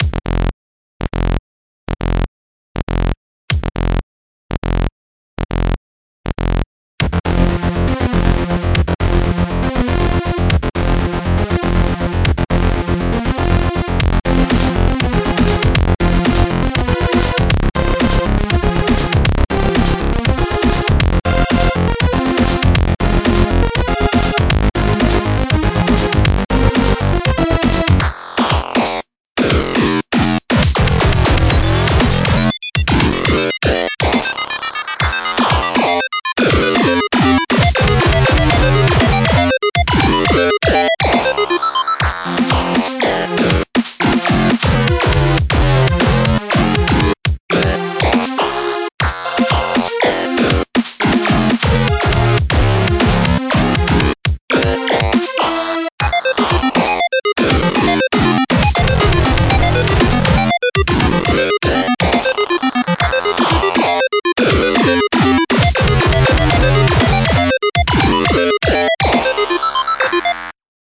This one features some interesting filtering and gabber kick action going on here.